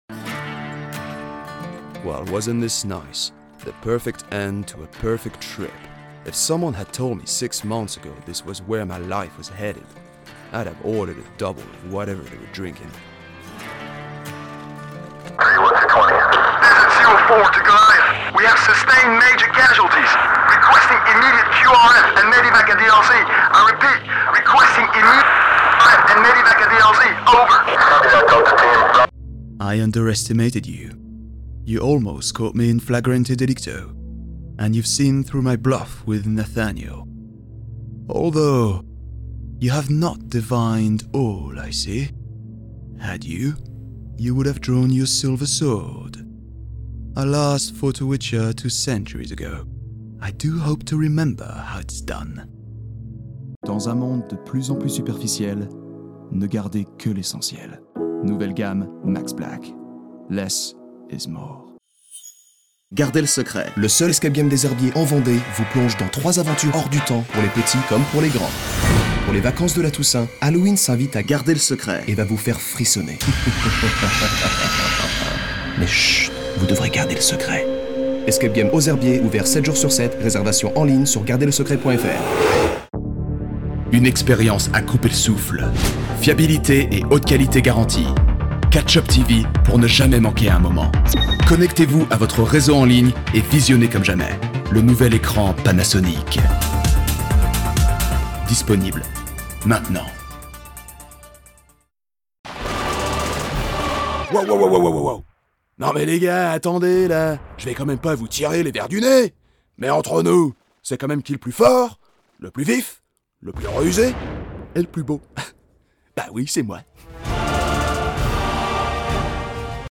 VOICE REEL
French Actor, perfect British and American English.